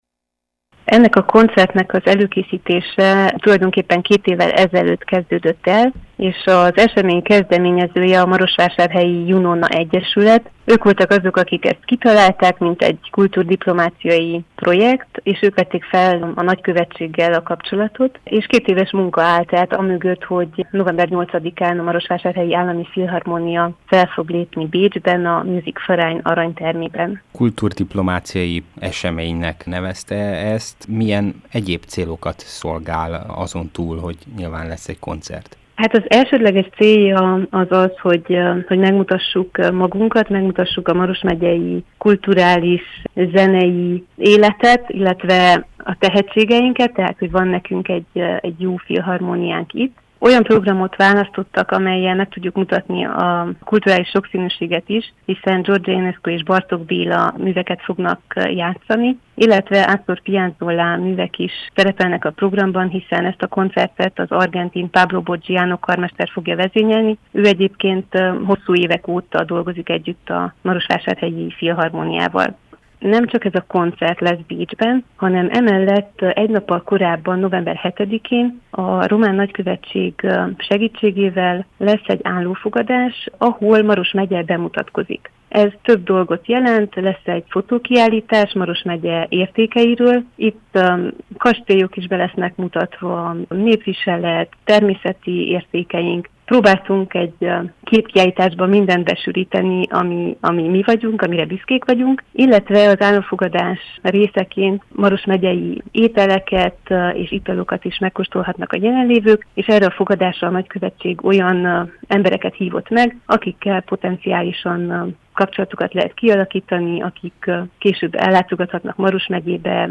nyilatkozott rádiónknak.